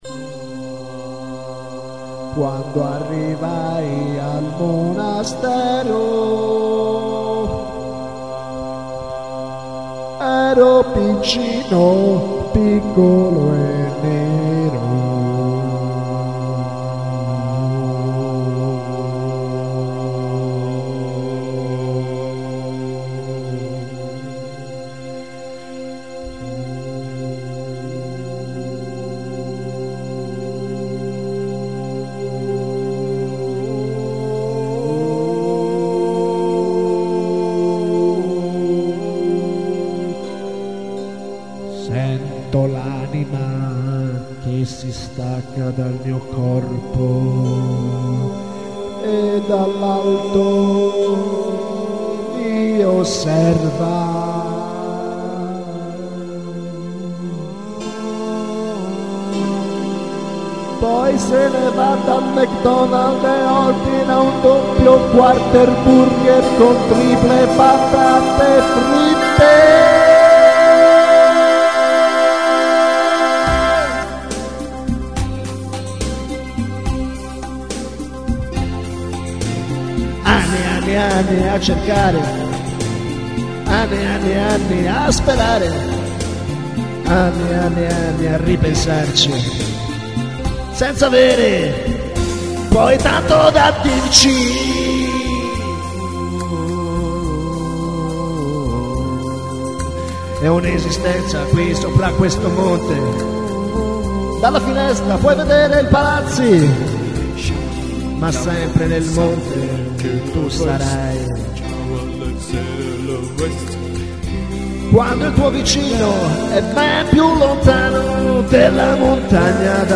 Un brano niueigg delirante, improvvisato